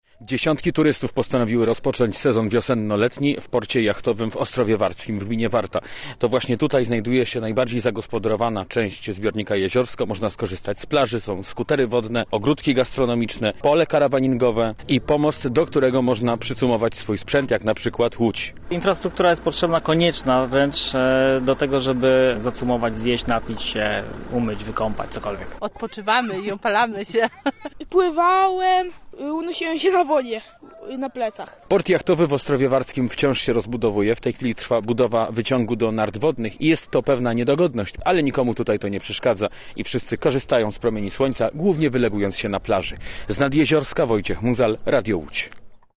Posłuchaj relacji naszego reportera: Nazwa Plik Autor Majówka nad Jeziorskiem audio (m4a) audio (oga) ZDJĘCIA, NAGRANIA WIDEO, WIĘCEJ INFORMACJI Z SIERADZA I REGIONU ZNAJDZIESZ W DZIALE “RADIO ŁÓDŹ NAD WARTĄ”.